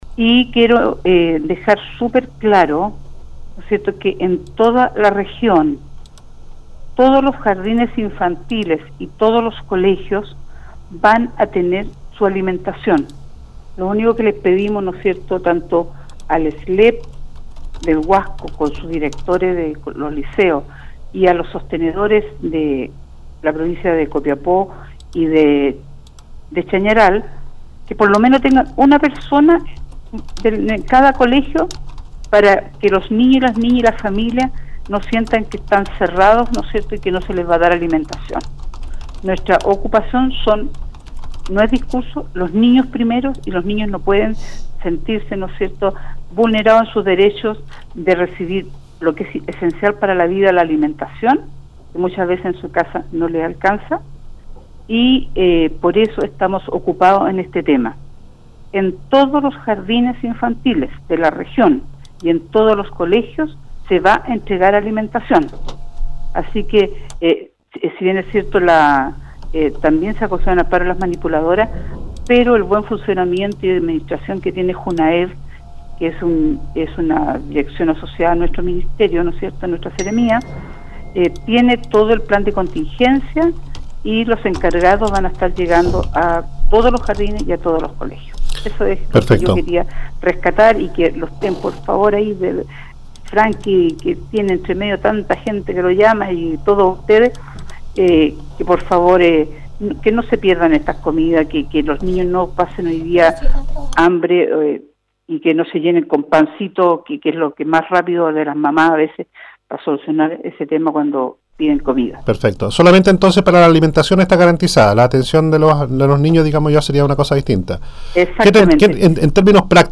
Audio de la entrevista con la Seremi de Educación de Atacama: